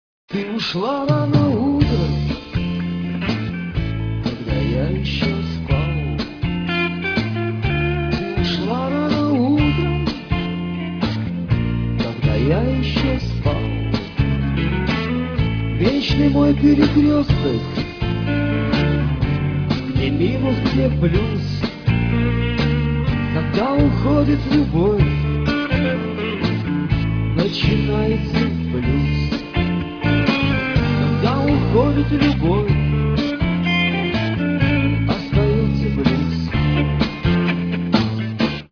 Шаболовка (1994)